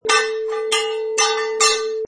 Swords Short Clash 3
Two swords clash
Product Info: 48k 24bit Stereo
Try preview above (pink tone added for copyright).
Tags: metal